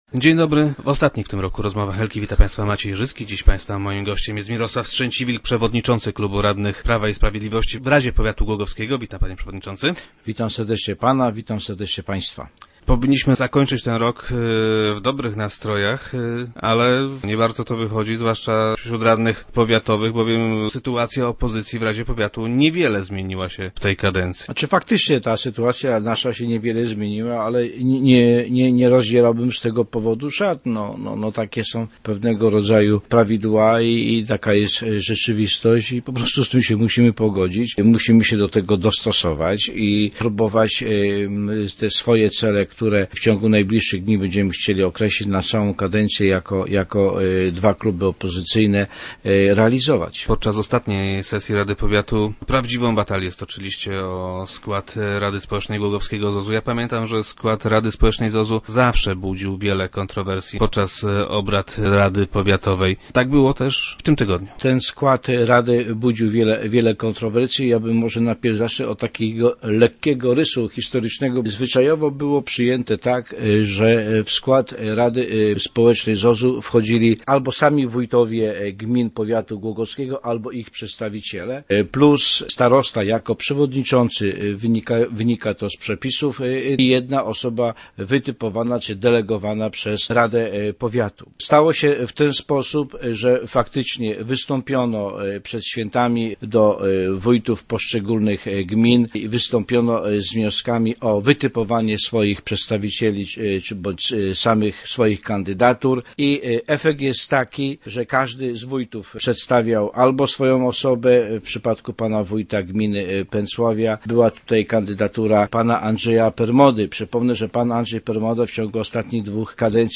Gościem Rozmów Elki był Mirosław Strzęciwilk, przewodniczący klubu radnych PiS.